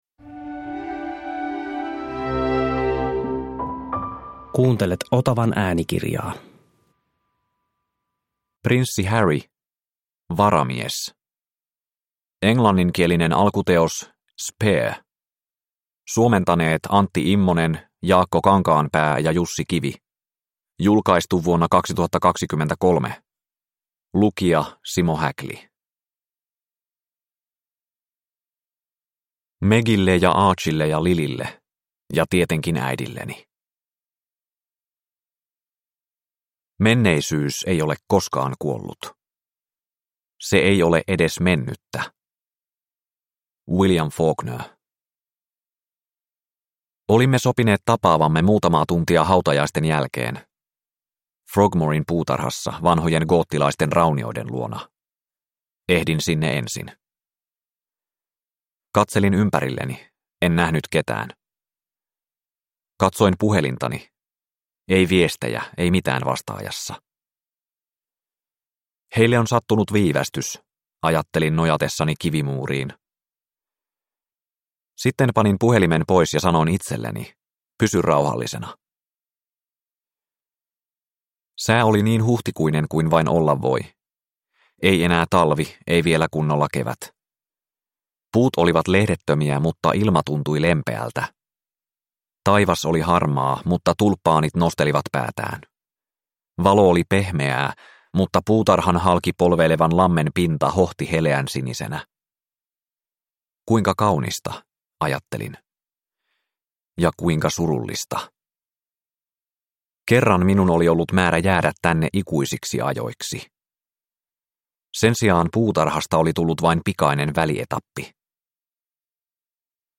Varamies – Ljudbok – Laddas ner